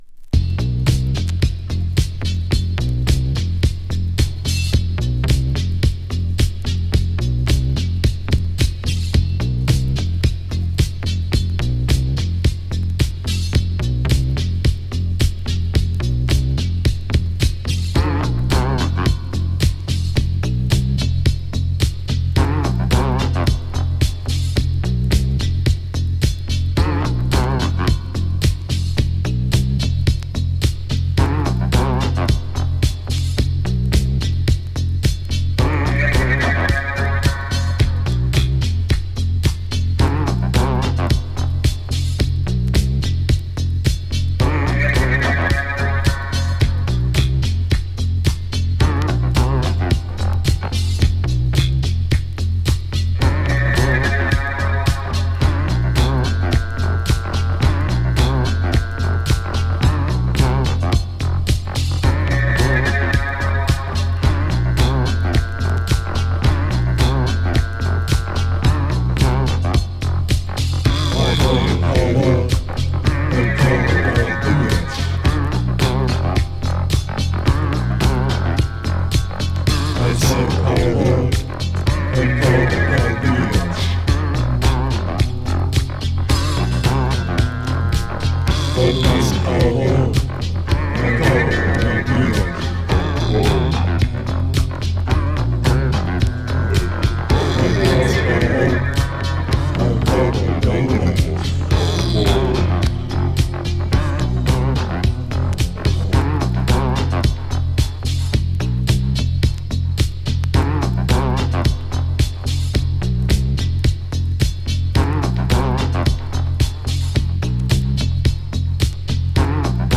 ギターもイイ感じに効いたミッドテンポの女性ボーカル・ブギーに。